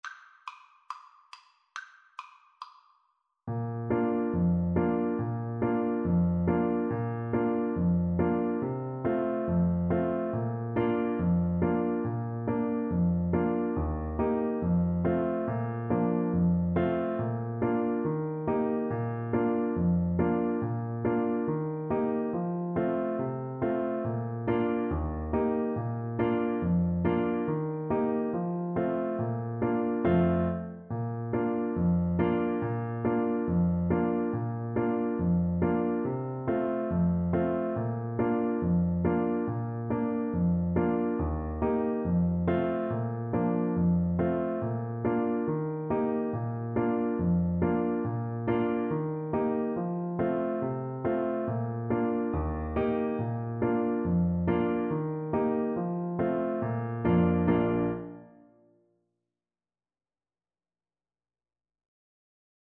Clarinet
Bb major (Sounding Pitch) C major (Clarinet in Bb) (View more Bb major Music for Clarinet )
4/4 (View more 4/4 Music)
Traditional (View more Traditional Clarinet Music)